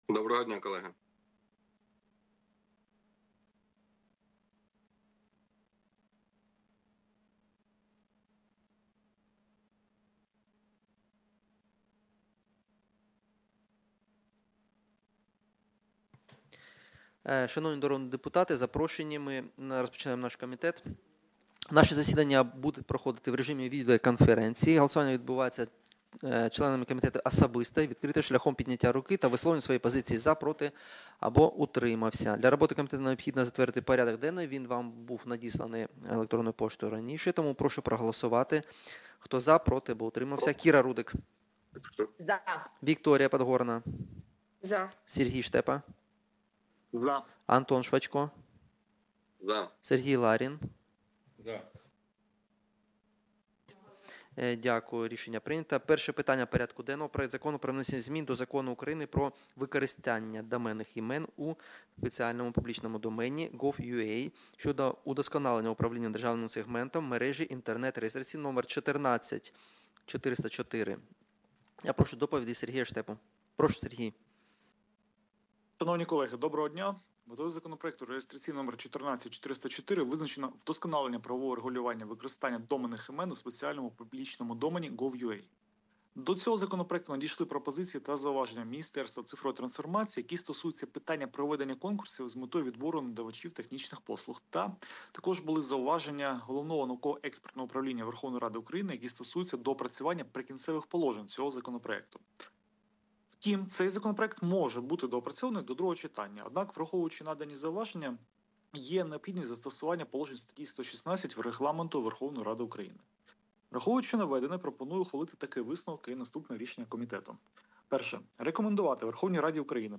Аудіозапис засідання Комітету від 02.03.2026